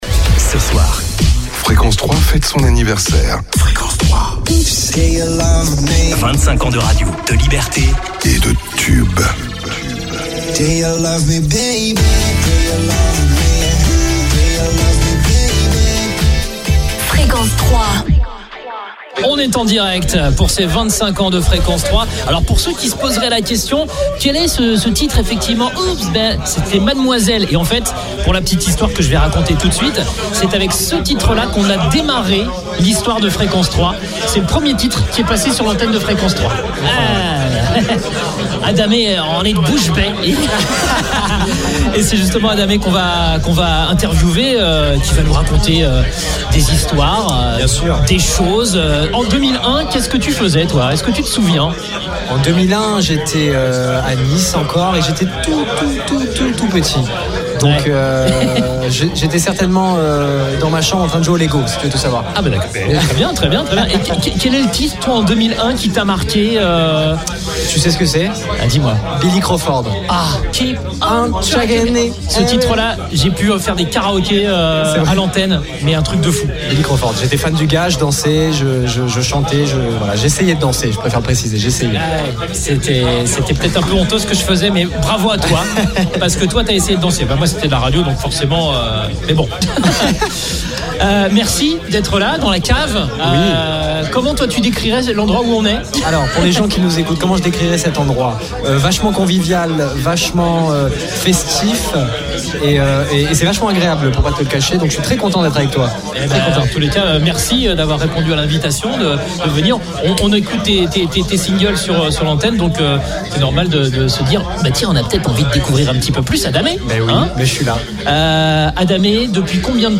Parmi les invités de la soirée des 25 ans de Fréquence 3 qui s’est tenue